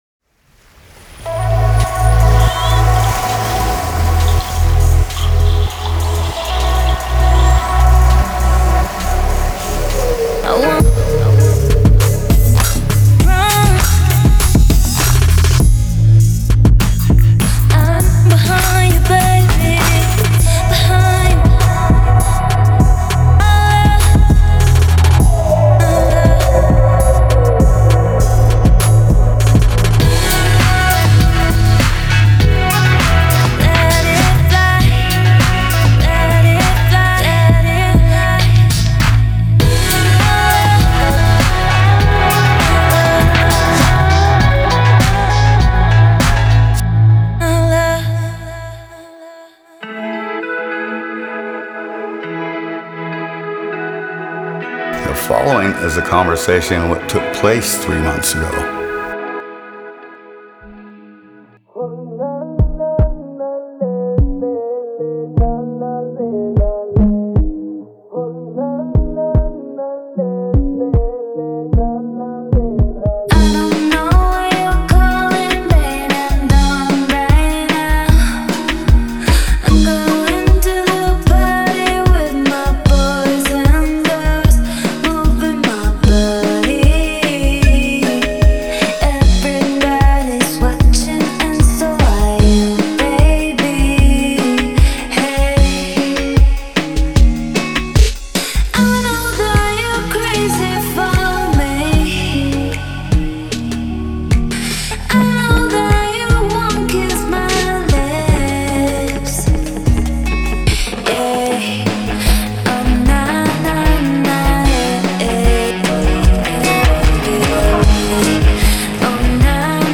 If you like pop music you'll like this one!